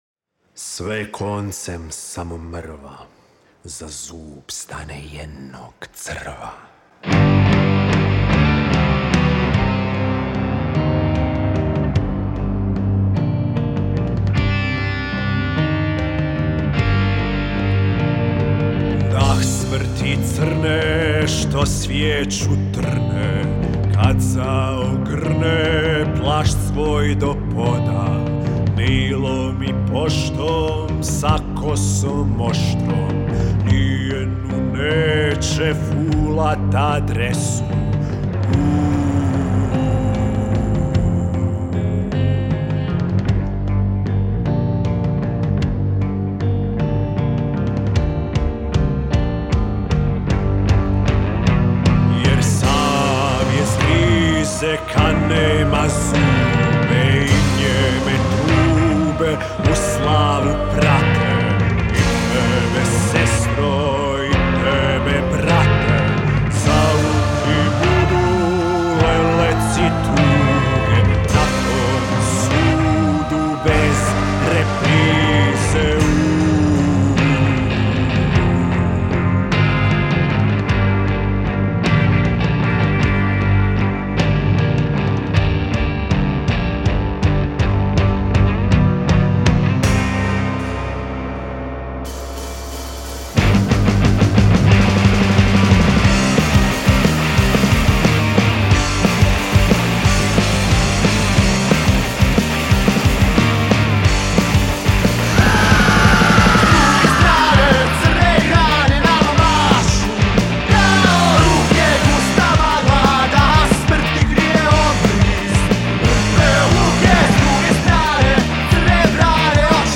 Mračni memento mori punk metal gospel.